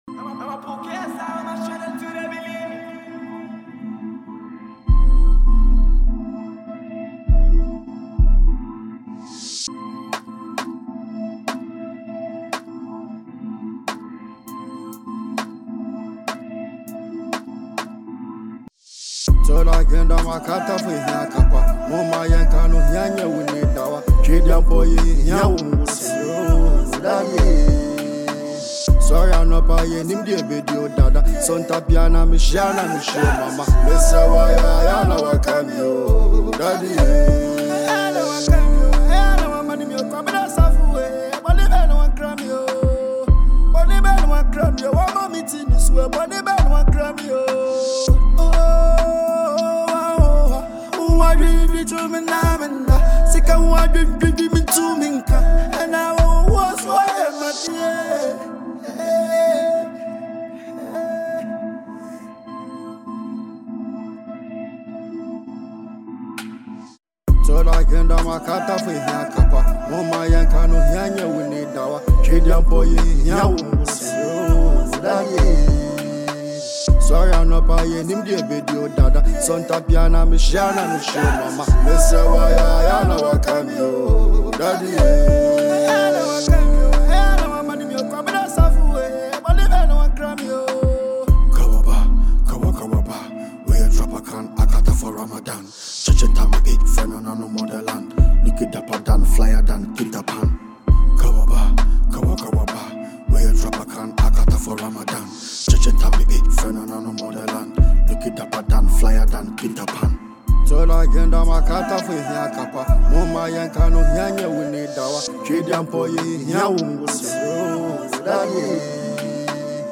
a Ghanaian asakaa artist